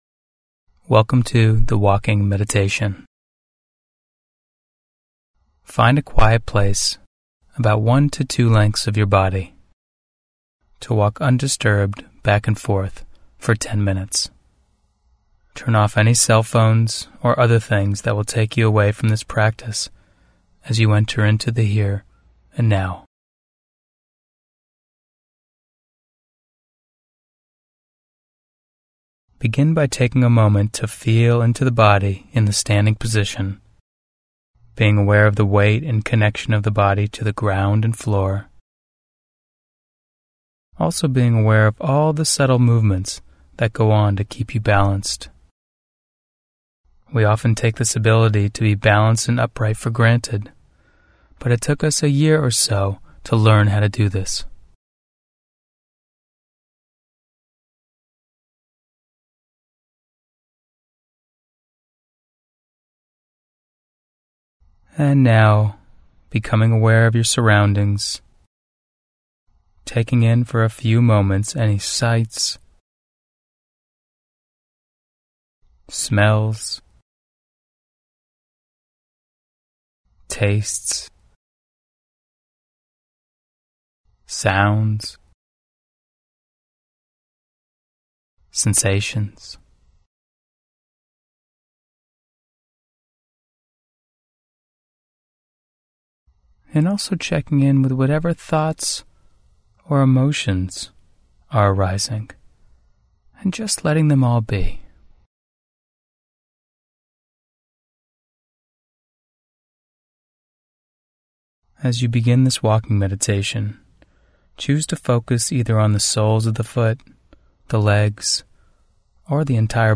05-mbsrwb-walking-max-10min.mp3